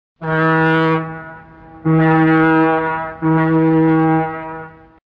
Звуки корабля, теплохода